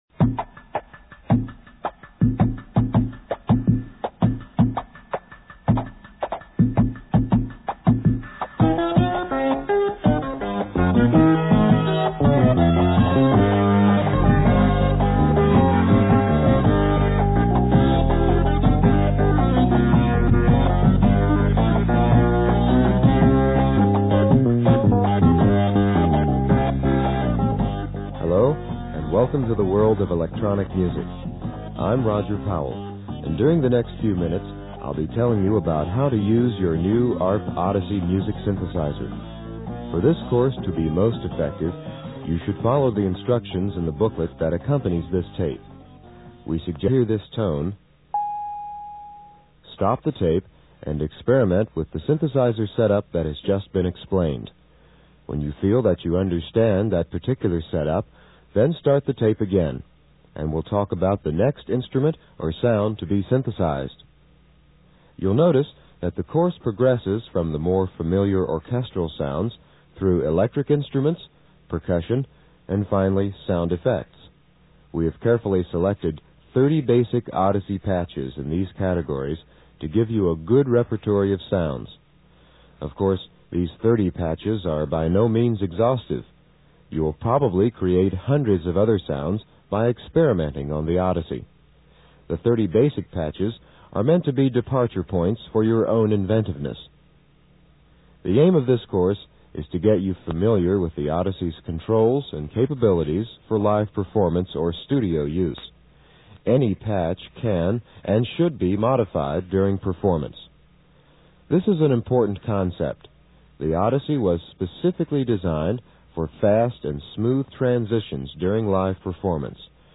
40 Minute Audio Tutorial in MP3 Format